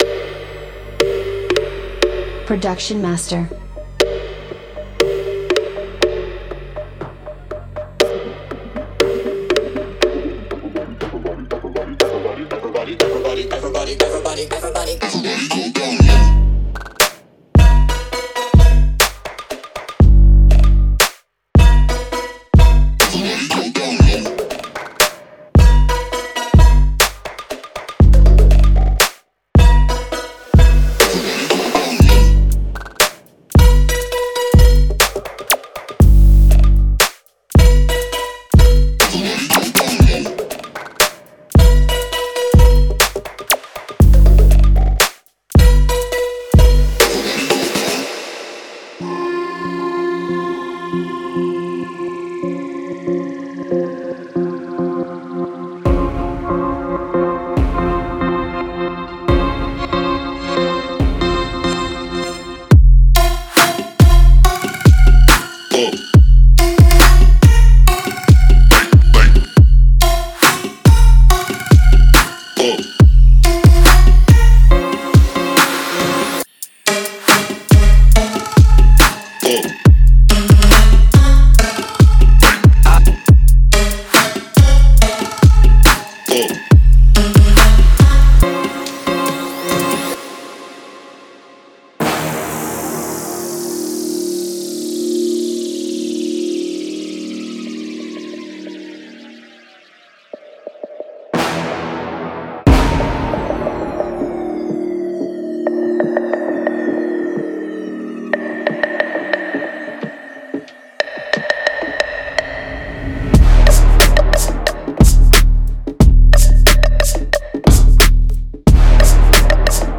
平均值808：  在您内部将发现您制作陷阱所需要的最具侵略性，恶劣的808声音。
绝对野蛮的军鼓，有力的踢脚和锋利的帽子只是这些背包所藏的严重邪恶鼓收藏的掌握。
坚硬的打击乐一杆射击，可怕的乐器环，狠狠的铜刺，肮脏的尖叫声和节拍的眨眼环绝对会摧毁舞池。